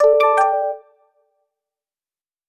Alert (2).wav